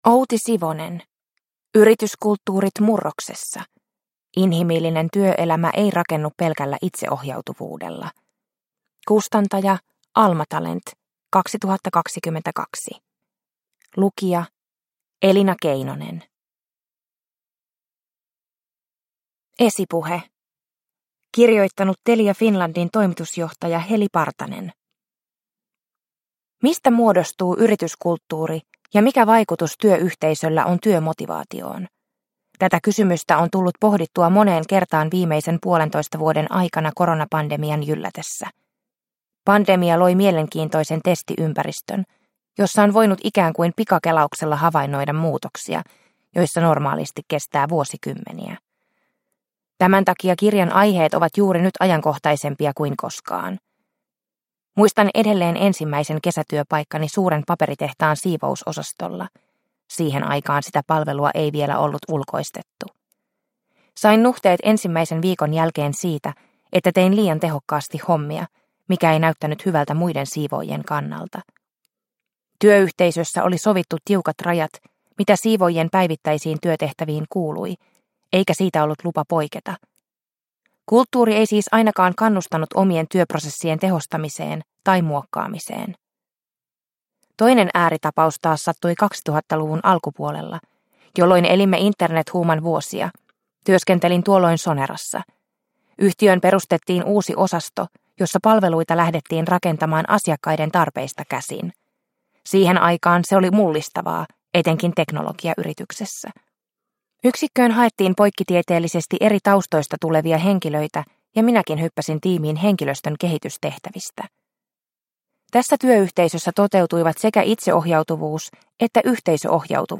Yrityskulttuurit murroksessa – Ljudbok